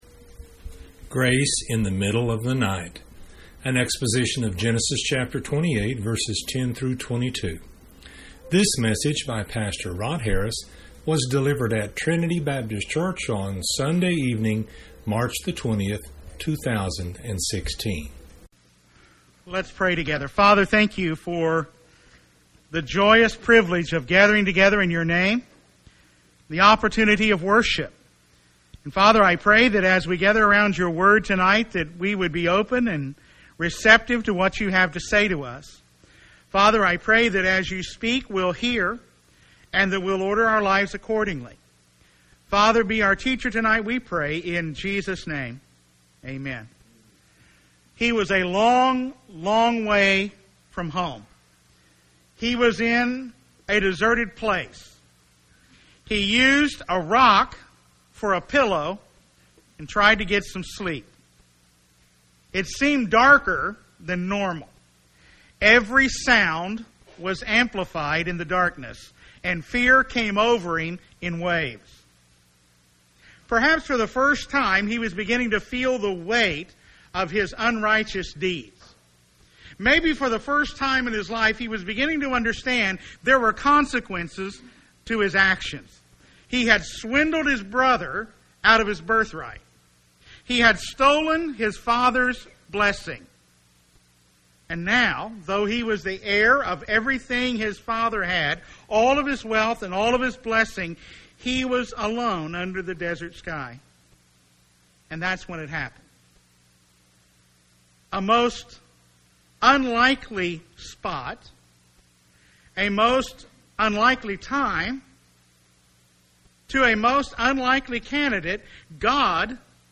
delivered at Trinity Baptist Church on Sunday evening